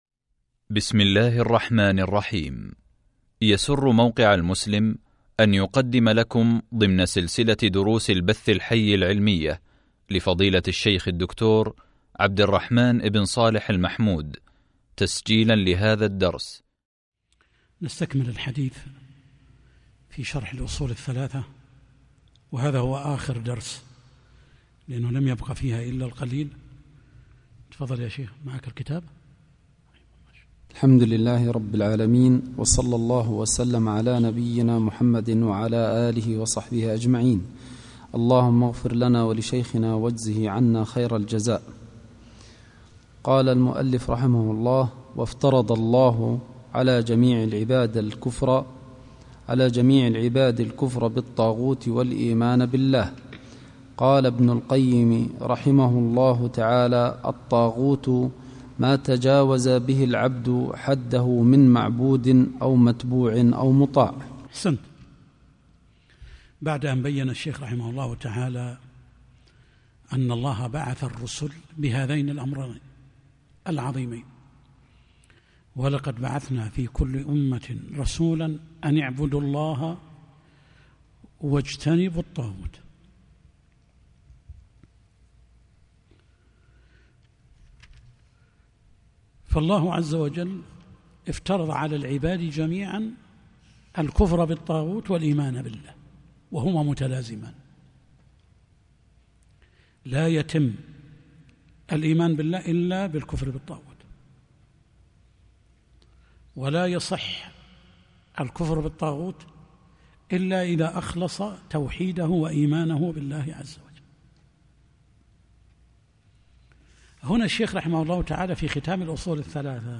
شرح الأصول الثلاثة | الدرس 23 والأخير | موقع المسلم